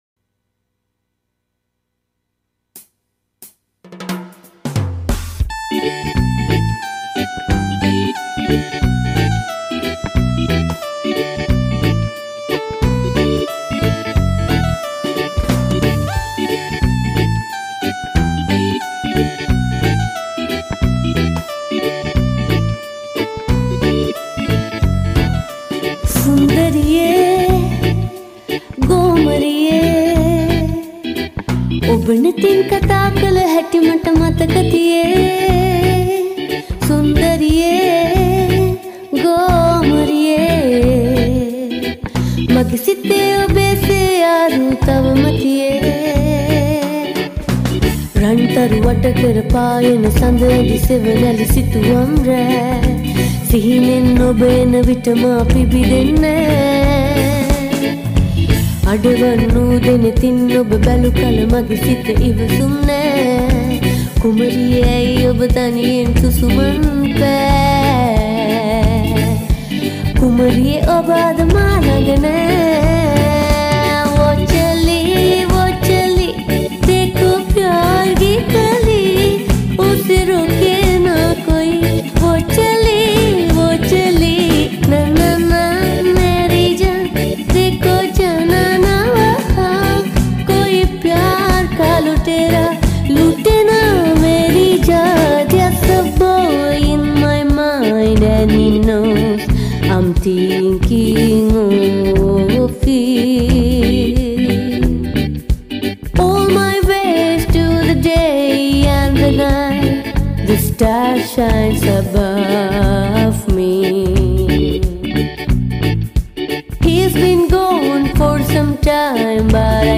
Reggae cover